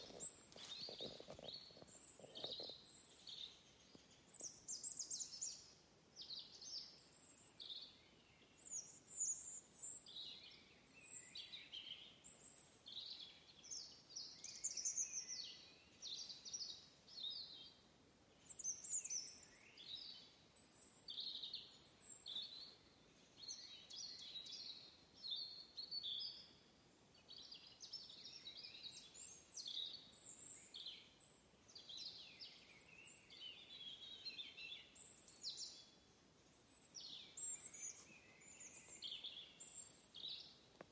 Sarkanrīklīte, Erithacus rubecula
StatussDzied ligzdošanai piemērotā biotopā (D)
Vieta - skujkoku mežs pie Gaujas vecupes.